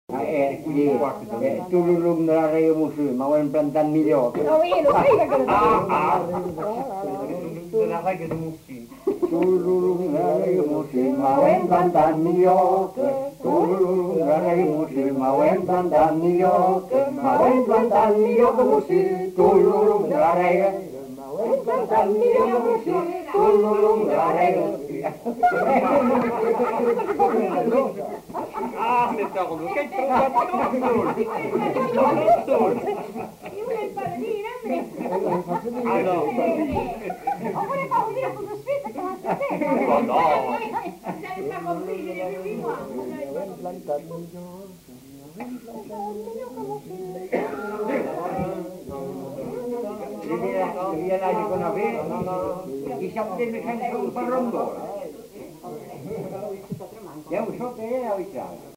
Aire culturelle : Grandes-Landes
Lieu : Luxey
Genre : chant
Type de voix : voix mixtes
Production du son : chanté
Danse : rondeau